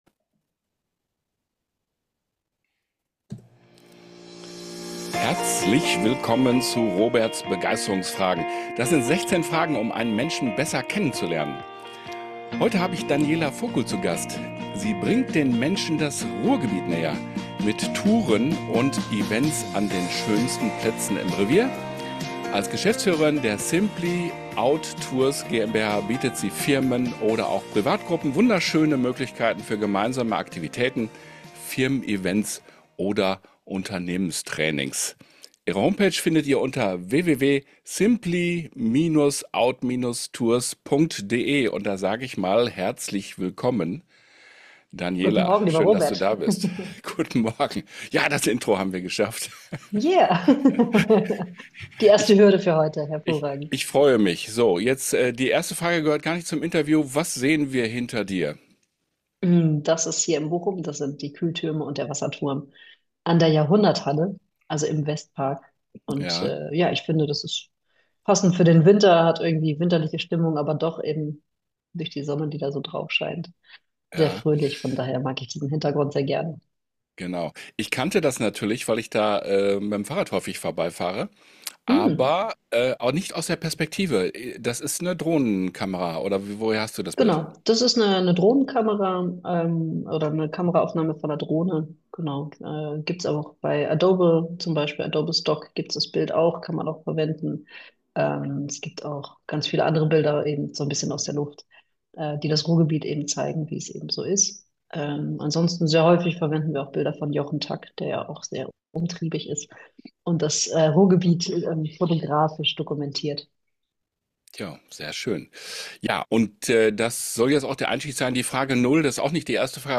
Mein "Interview" als Podcast (Tondatei) finden Sie unter
Live und ungeschnitten auf der Online-Plattform Zoom.